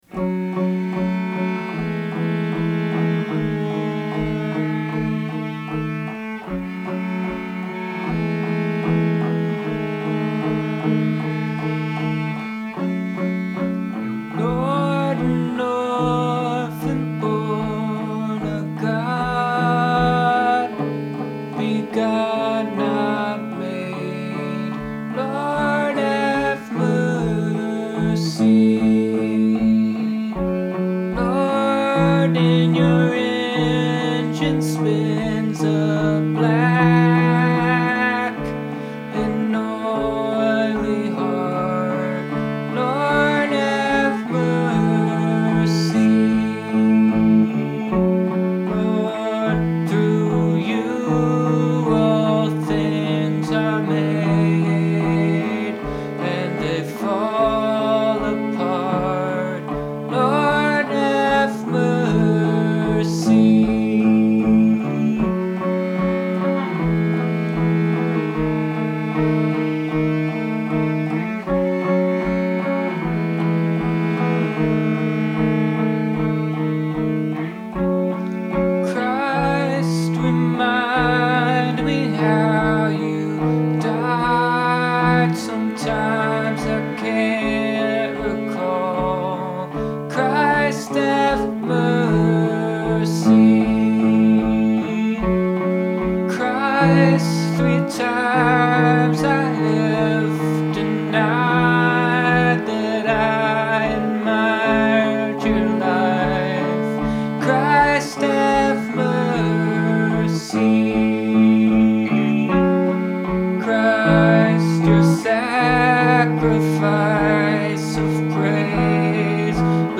it sounds to me like you're playing mainly two strings, so i'm making some leaps here by assuming full chords that may or may not be what you had in mind. the harmonic ambiguity is one of the things that makes it wonderful, so i'm sorry if trying to nail it down wrings all the mystery out of it. but that's all i know how to do.
Tuning:
verse, verse, verse